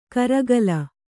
♪ karagala